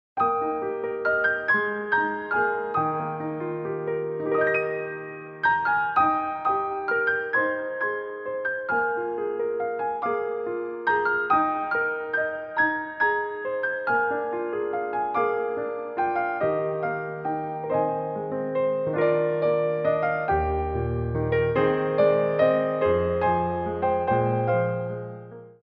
Ronds de Jambé en L'air
3/4 (8x8)